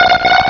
Cri de Natu dans Pokémon Rubis et Saphir.